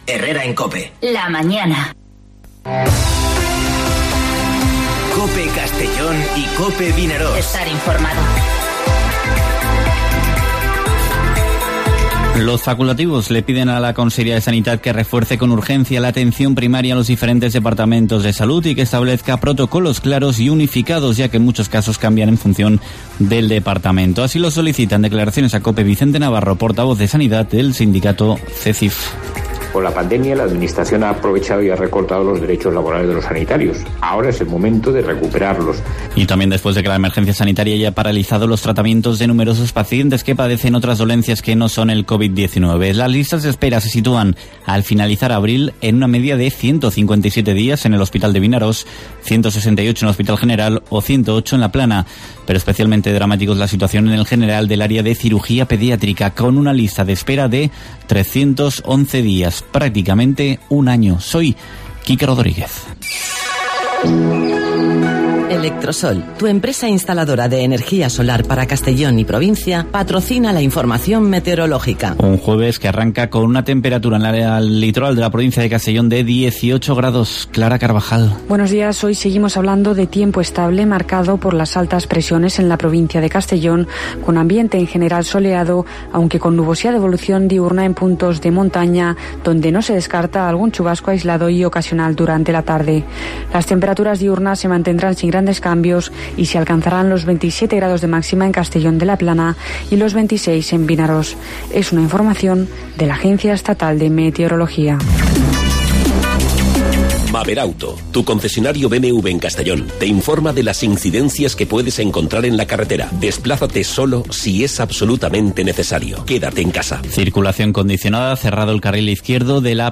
Informativo Herrera en COPE en la provincia de Castellón (21/05/2020)